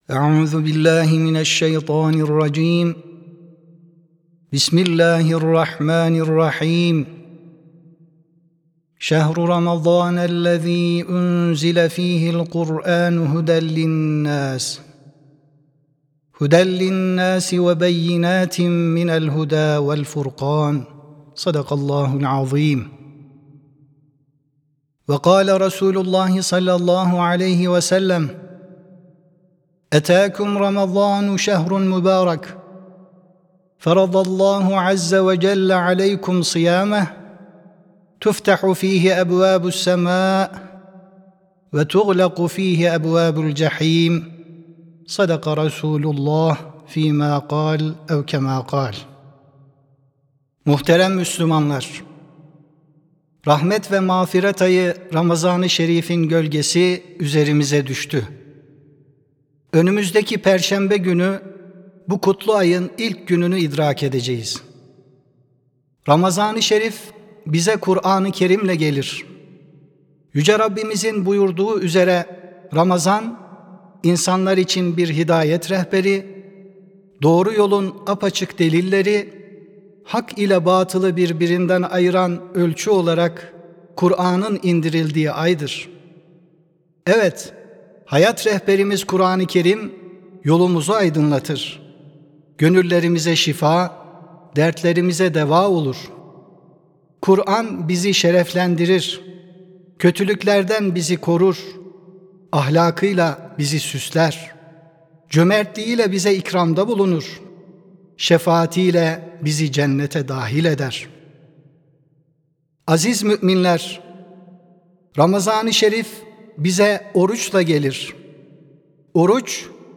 13 Şubat 2026 Tarihli Cuma Hutbesi
Sesli Hutbe (Ramazan İklimi).mp3